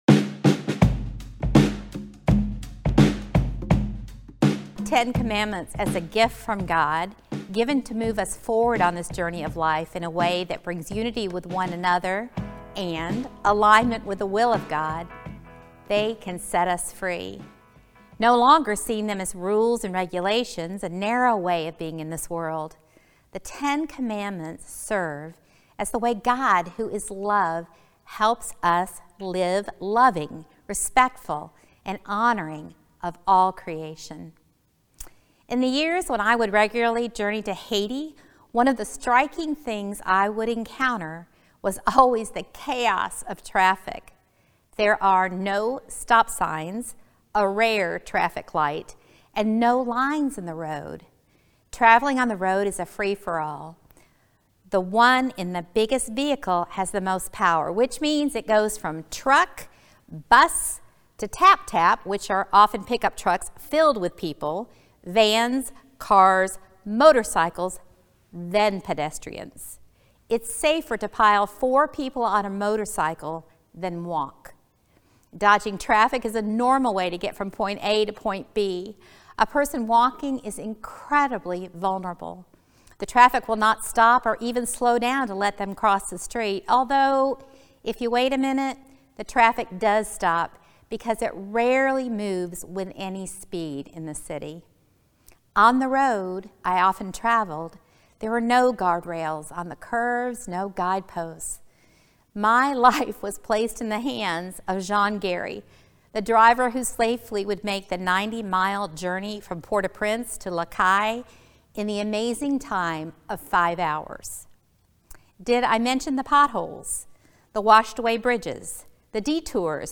Sermons | The Downtown Church